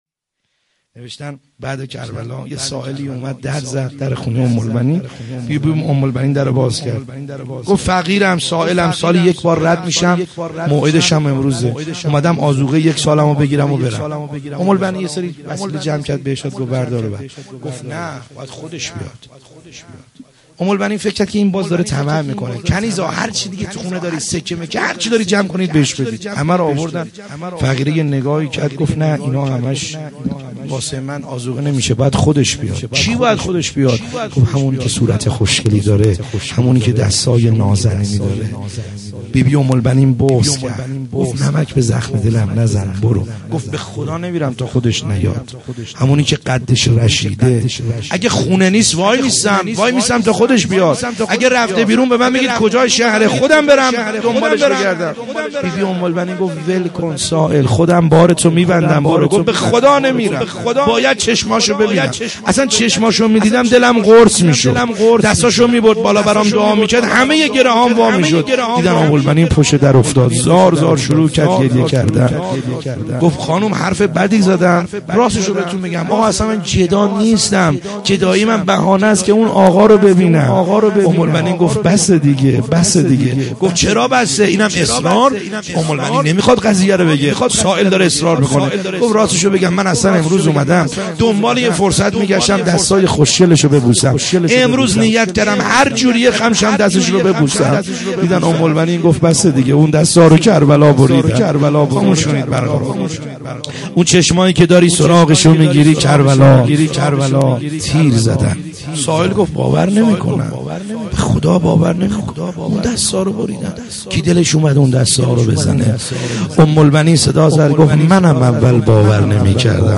خیمه گاه - بیرق معظم محبین حضرت صاحب الزمان(عج) - روضه